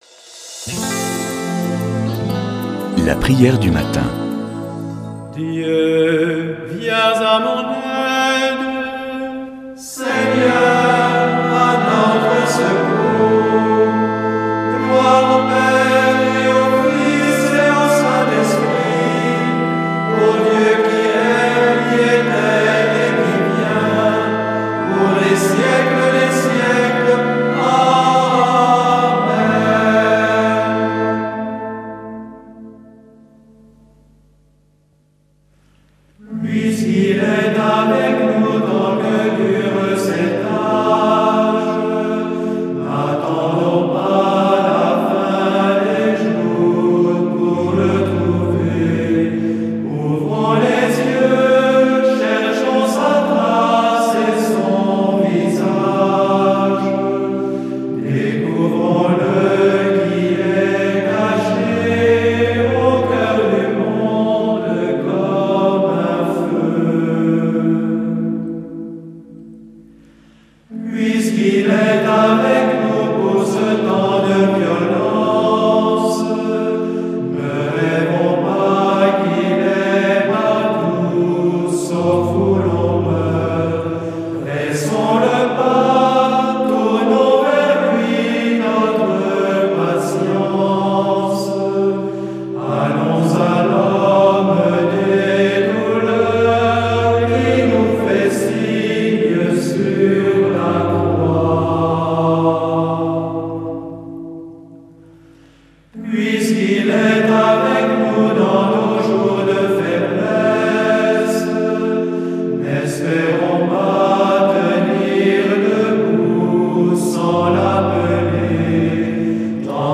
Prière du matin
ABBAYE DE TAMIE